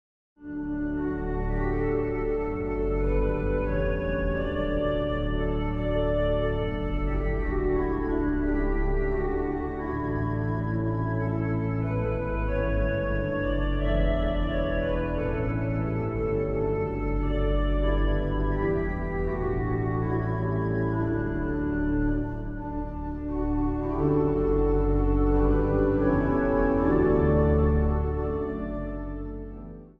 harp | vanuit de Johanneskerk te Breda-Princenhage.
Instrumentaal | Harp